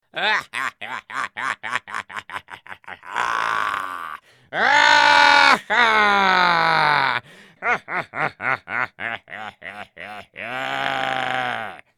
03021 cartoon villain laughter
bad cartoon evil laugh laughing laughter male villain sound effect free sound royalty free Funny